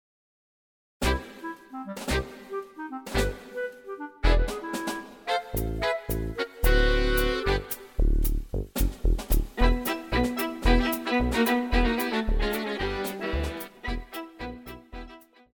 Pop
Viola
Instrumental
World Music,Fusion
Only backing